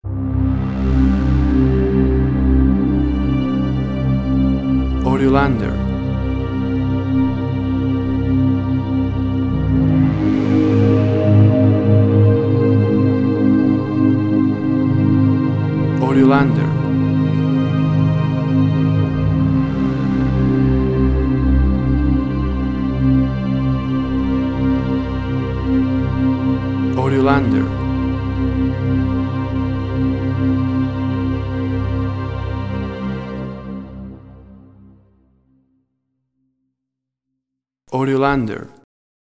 WAV Sample Rate: 24-Bit stereo, 48.0 kHz